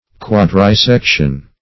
Search Result for " quadrisection" : The Collaborative International Dictionary of English v.0.48: Quadrisection \Quad`ri*sec"tion\, n. [Quadri- + section.] A subdivision into four parts.
quadrisection.mp3